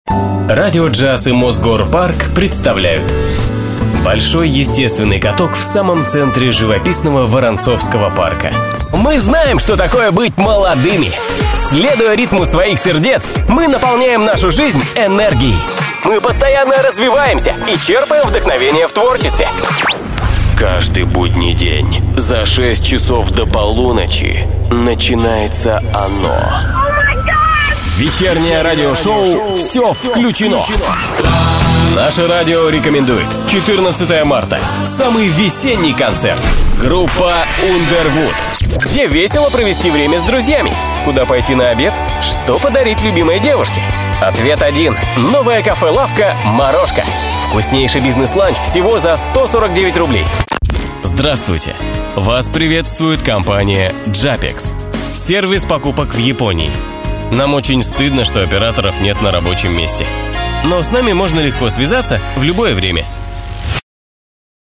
Voice Over
Rusça seslendirme uzmanı. Erkek ses, ticari projeler ve kurumsal anlatımlar için profesyonel performans.